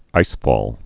(īsfôl)